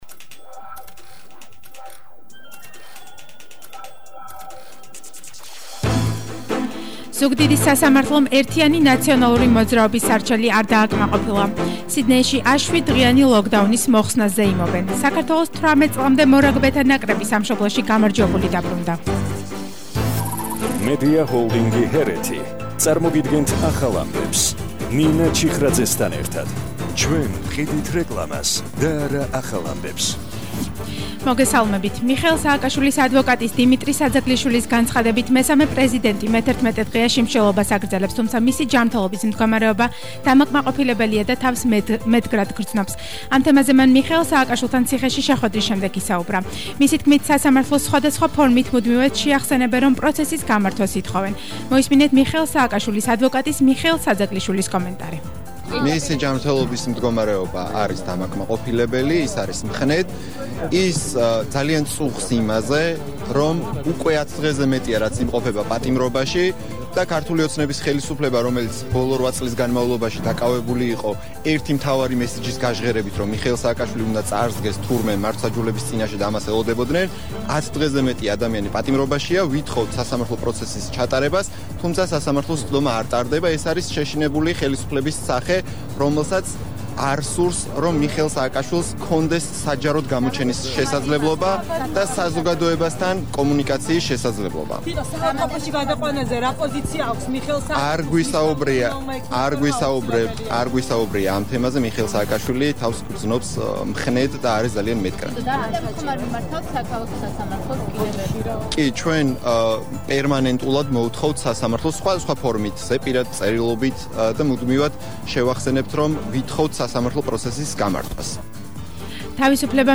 ახალი ამბები 14:00 საათზე –11/10/21